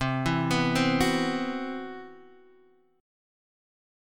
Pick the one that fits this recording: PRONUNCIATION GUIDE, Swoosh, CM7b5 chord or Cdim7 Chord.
CM7b5 chord